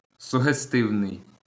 sugestivnij
wymowa: